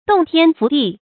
洞天福地 dòng tiān fú dì 成语解释 道家指神道居处。
成语简拼 dtfd 成语注音 ㄉㄨㄙˋ ㄊㄧㄢ ㄈㄨˊ ㄉㄧˋ 常用程度 常用成语 感情色彩 褒义成语 成语用法 联合式；作宾语、定语；含褒义 成语结构 联合式成语 产生年代 古代成语 成语正音 福，不能读作“fǔ”。